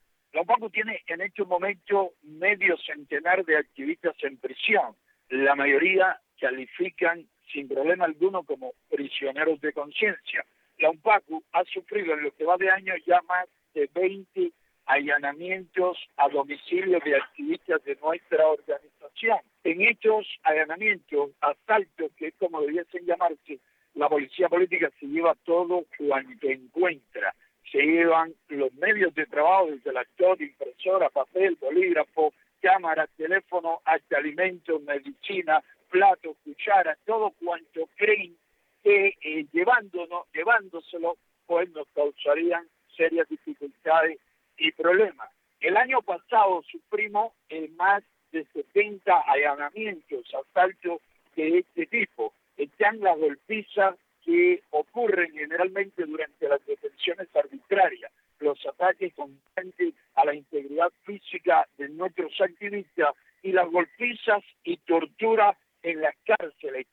(Con entrevistas concedidas a Radio Martí)